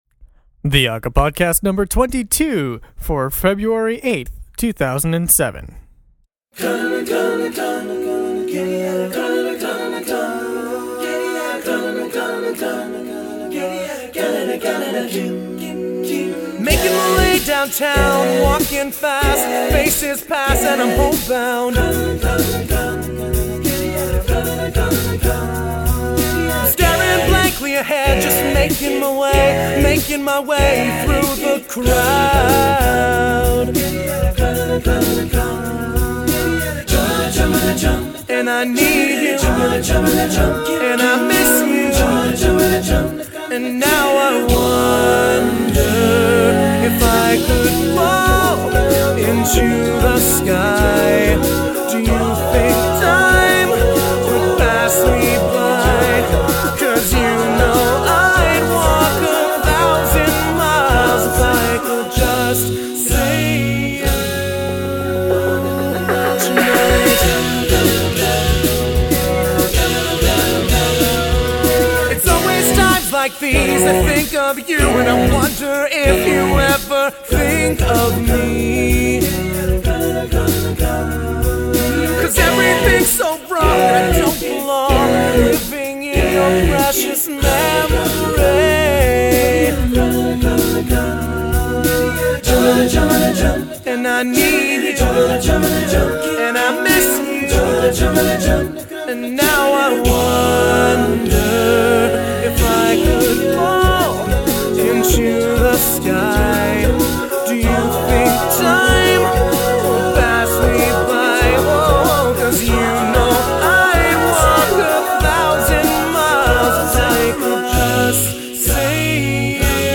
especially for all male voices